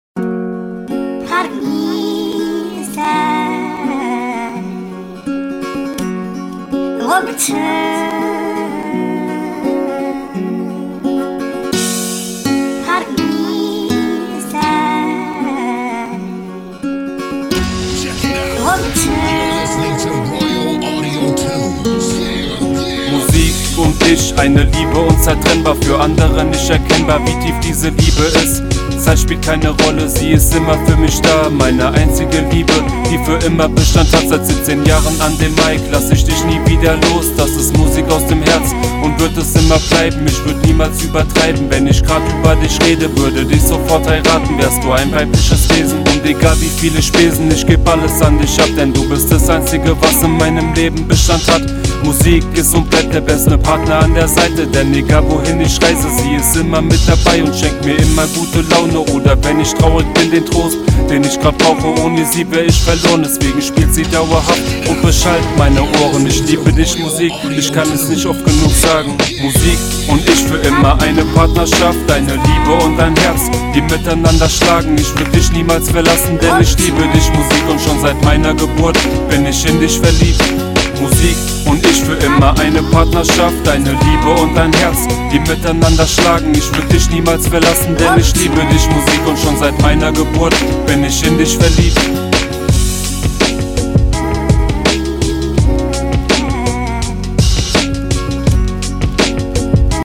Ich hab jetzt als einziges Störgeräusch nur noch den Lüfter vom Laptop.
In dem Lied hab ich 2 Spuren eine auf 0 DB und das Doppel bzw zweite Spur auf -9 DB. Der Refrain hat 4 Spuren.